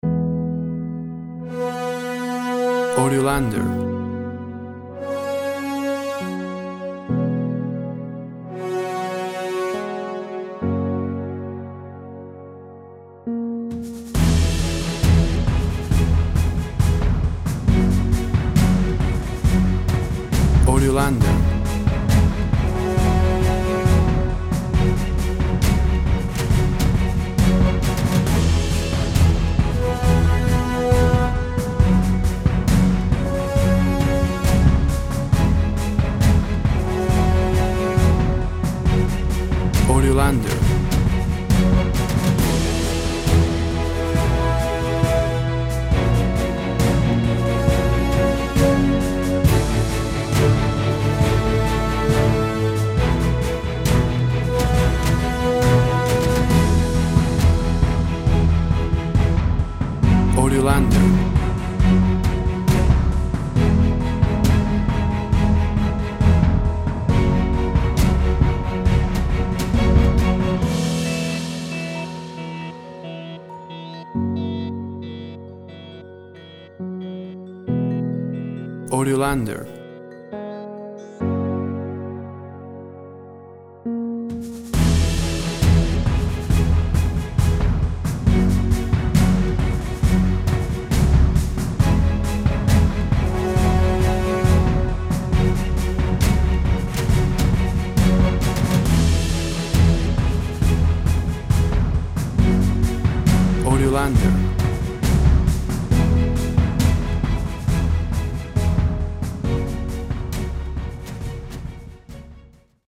WAV Sample Rate 24-Bit Stereo, 44.1 kHz
Tempo (BPM) 136